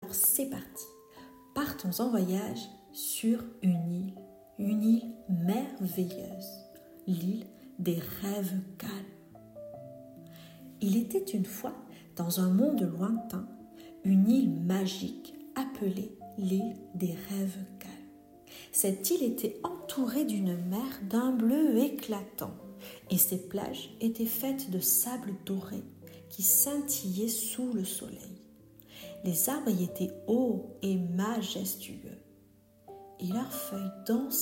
Bienvenue dans l’univers apaisant de « L’île des rêves calmes », une histoire conçue pour emmener vos enfants dans un voyage relaxant et hypnotique.